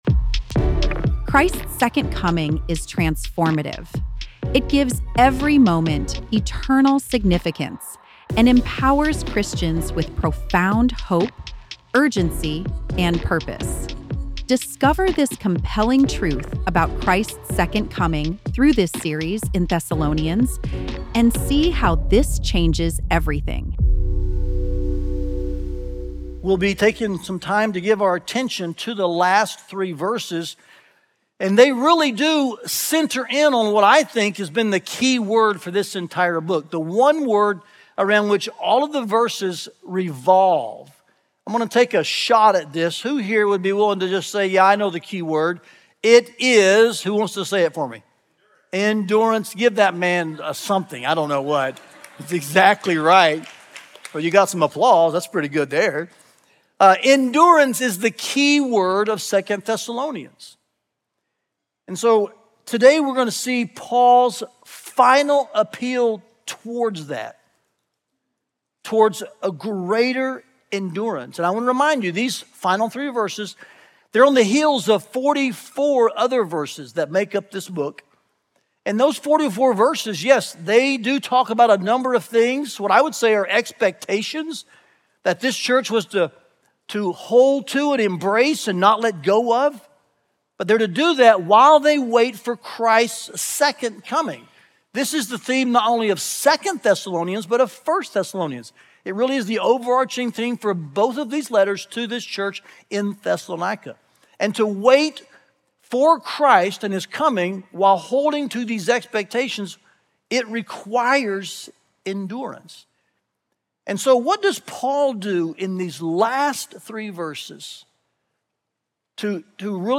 Listen to the final sermon in our series from 1–2 Thessalonians, and find past sermons from this series here.